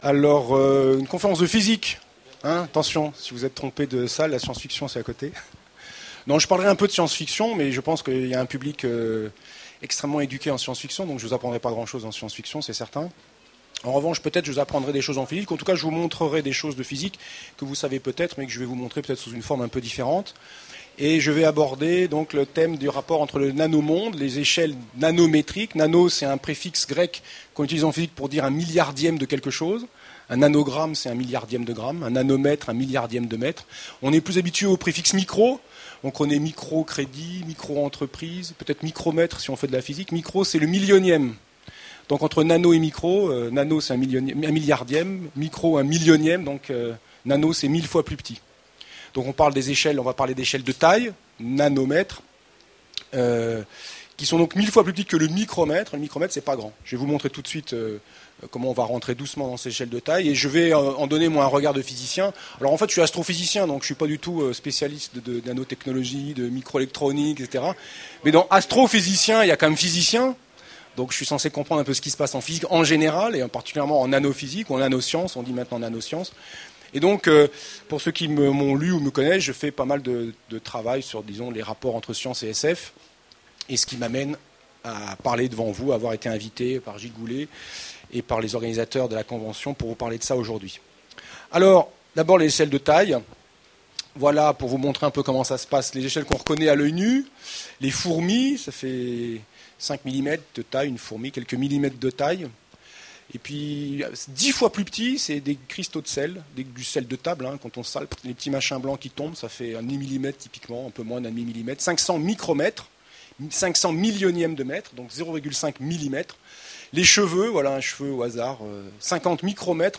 Voici l'enregistrement de la conférence Les nanotechnologies et la SF à la convention 2010